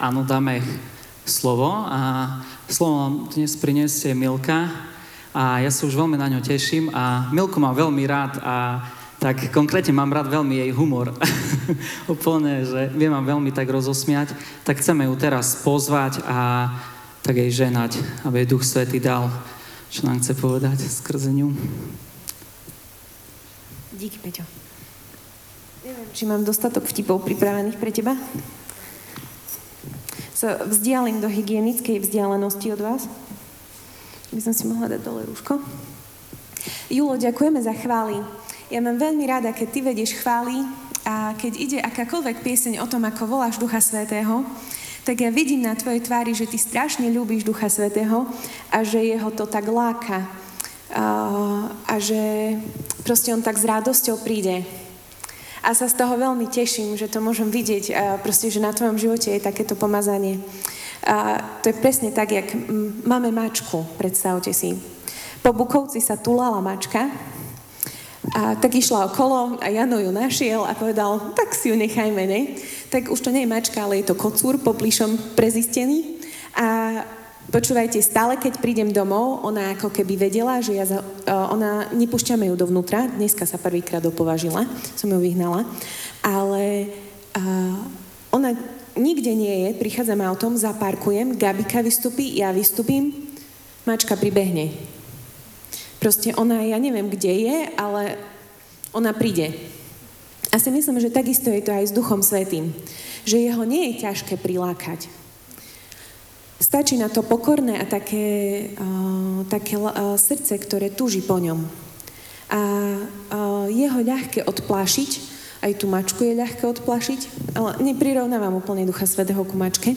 Audio kázeň